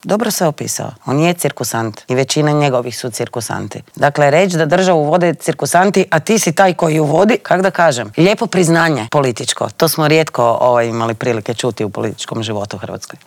O političkim aktualnostima, nadolazećim lokalnim izborima te o aktualnoj geopolitičkoj situaciji u svijetu razgovarali smo u Intervjuu tjedna Media servisa sa saborskom zastupnicom i koordinatoricom stranke Možemo! Sandrom Benčić.